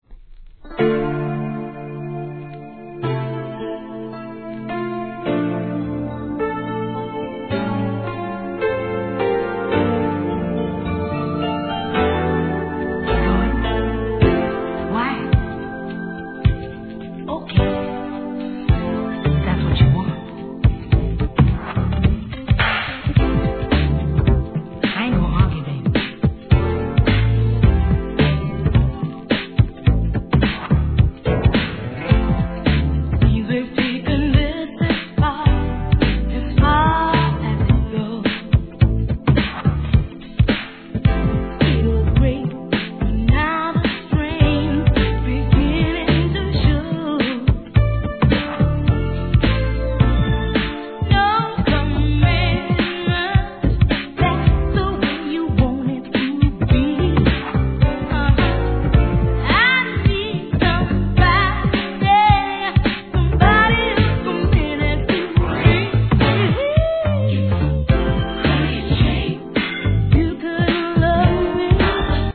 HIP HOP/R&B
洒落オツNEW JACK SWING!!